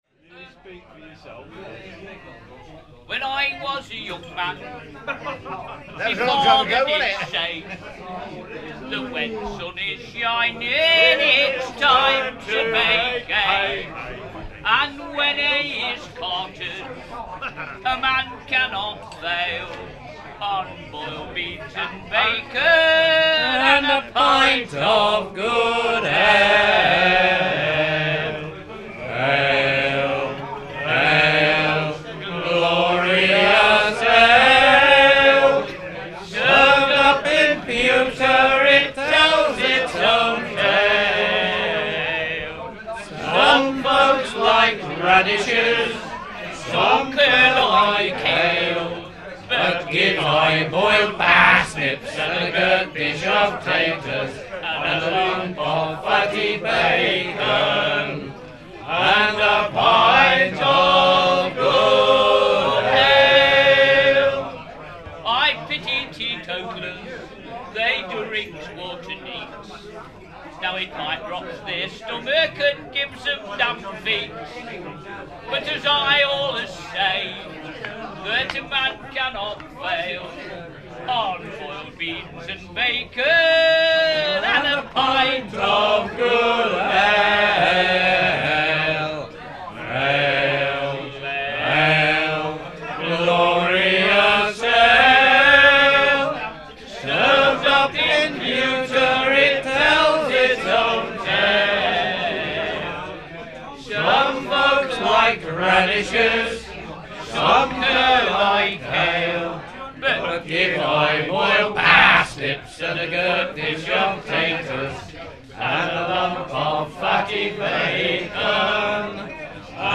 Morris songs